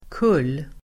Uttal: [kul:]